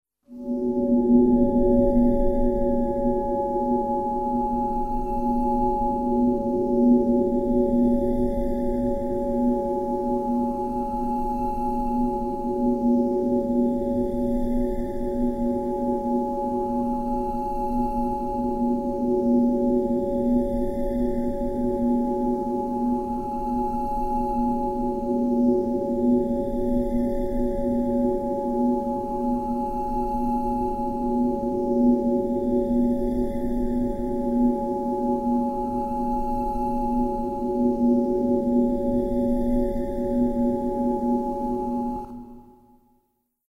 Efecto especial de voces en sueños 02